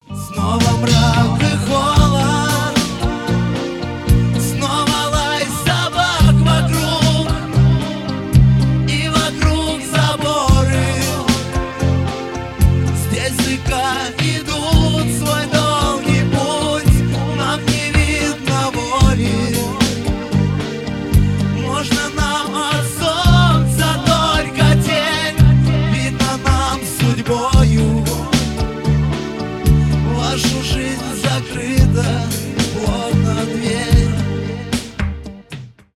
тюремная лирика , шансон , грустные